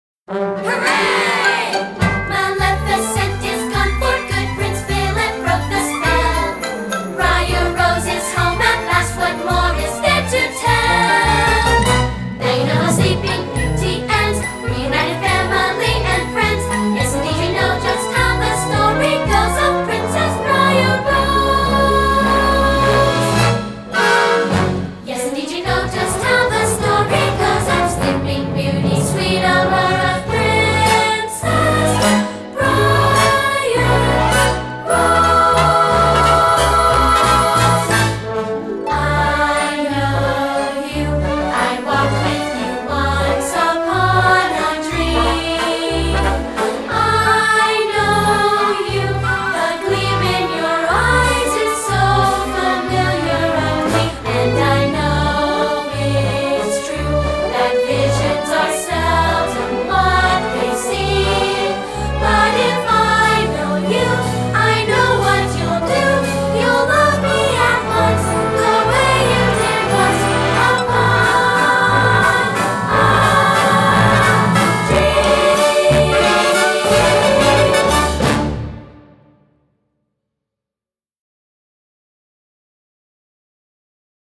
With Vocals